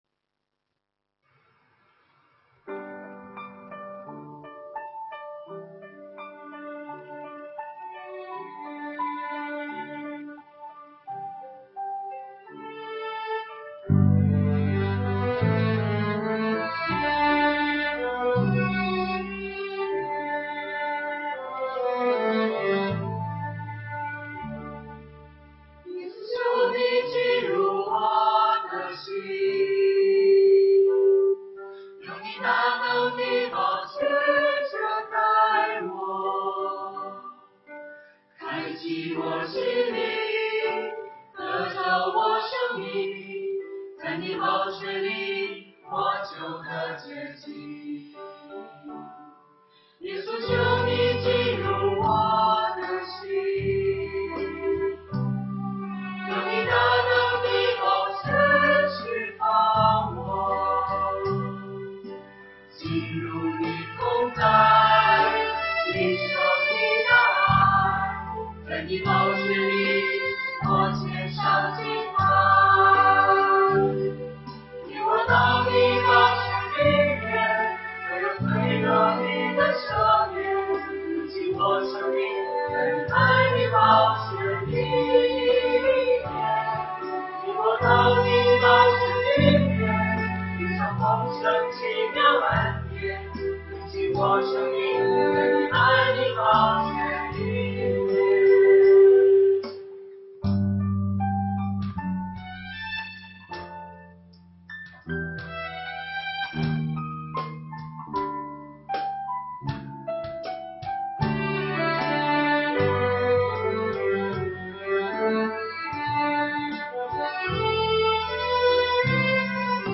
团契名称: 清泉诗班 新闻分类: 诗班献诗 音频: 下载证道音频 (如果无法下载请右键点击链接选择"另存为") 视频: 下载此视频 (如果无法下载请右键点击链接选择"另存为")